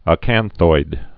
(ə-kănthoid)